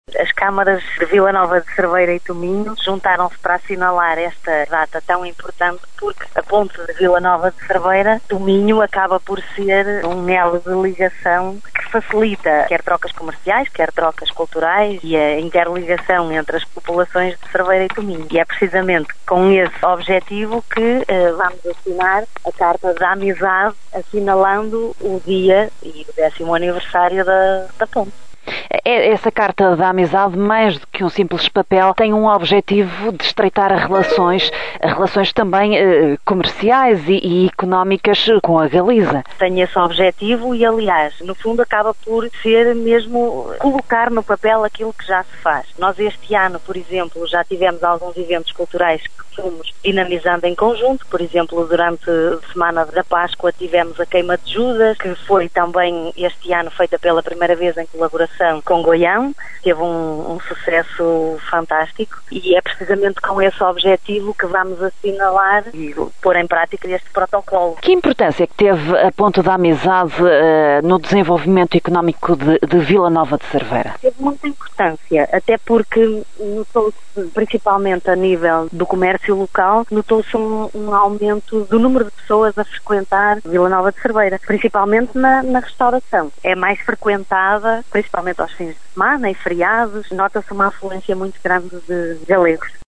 A vereadora cerveirense Aurora Viães revela pormenores deste projecto que procura unir e planear um futuro em comum.